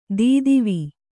♪ dīdivi